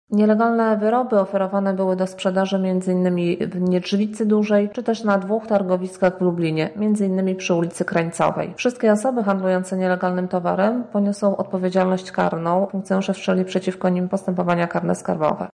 -mówi nadkomisarz